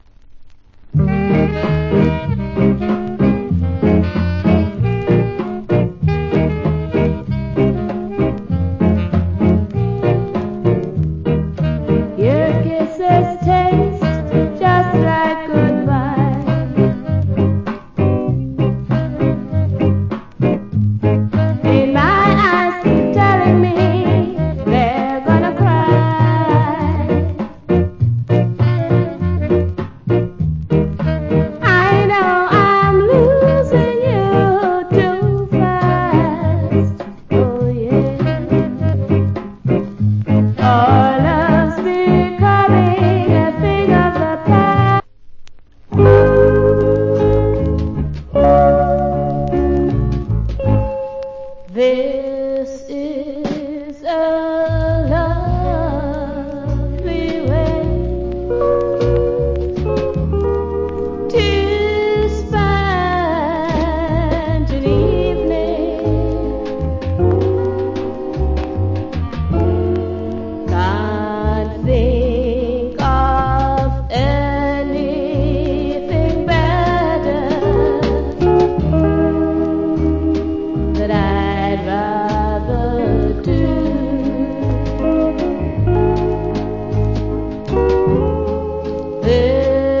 Very Rare Rock Steady.